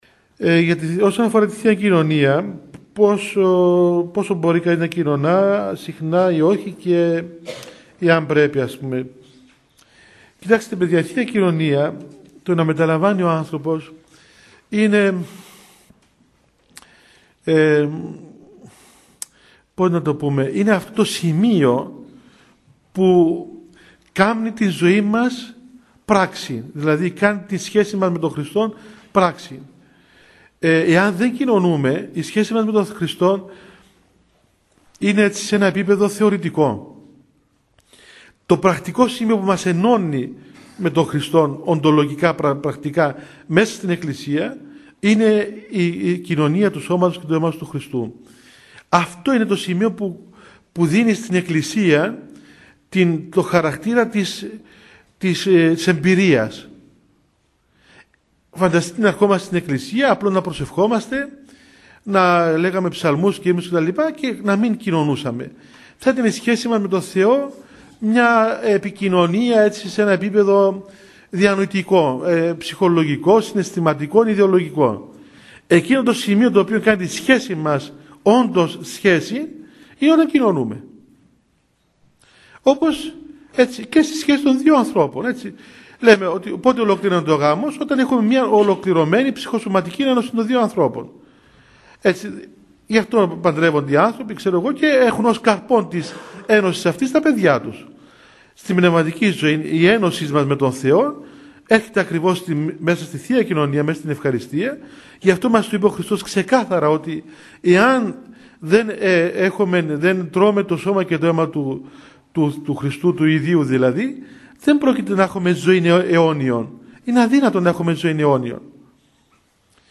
Ομιλίες / Ηχογραφήσεις Ορθοδοξία / Εκκλησία
Απαντά ο πατήρ Αθανάσιος, Μητροπολίτης Λεμεσού.